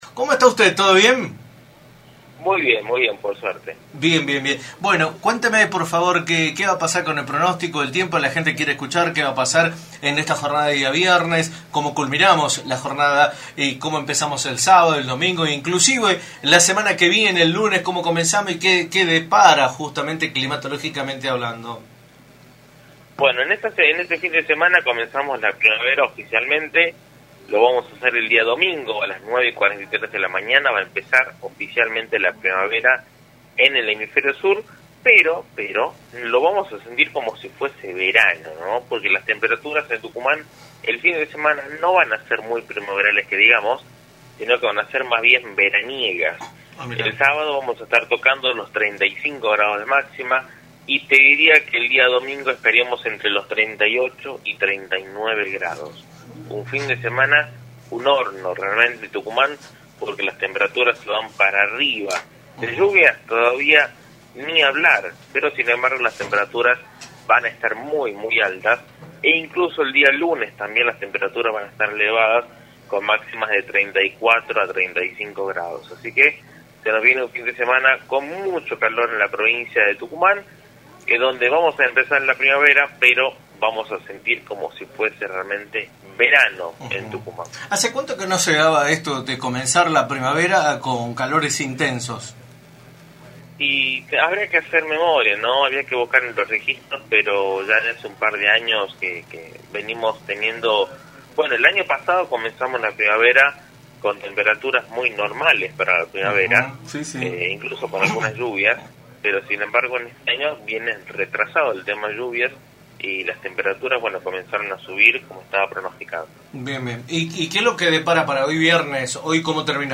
en “La Mañana del Plata”, por la 93.9.